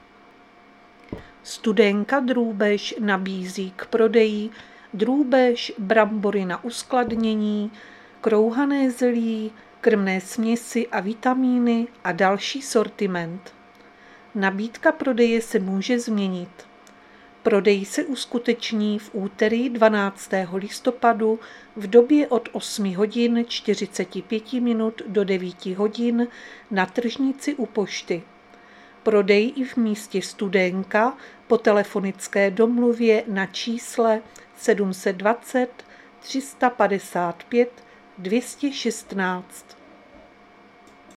Záznam hlášení místního rozhlasu 11.11.2024